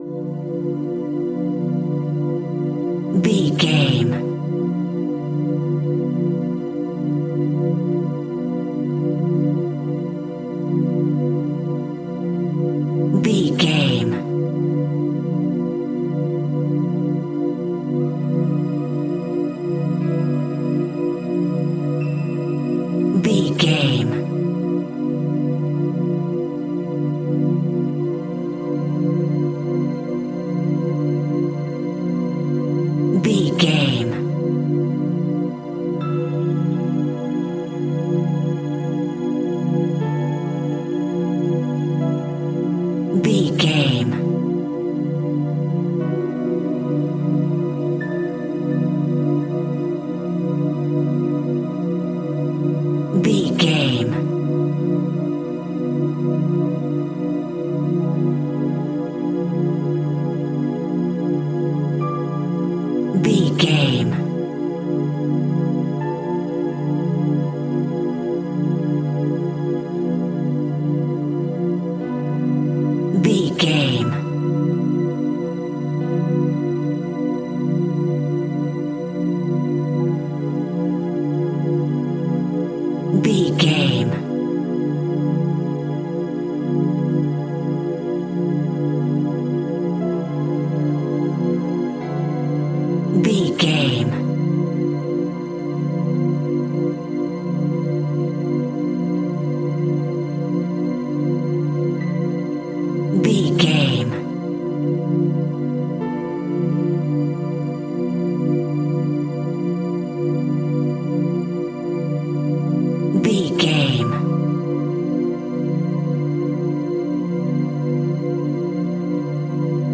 Aeolian/Minor
Slow
scary
tension
ominous
dark
suspense
haunting
eerie
ethereal
organ
piano
Synth Pads
atmospheres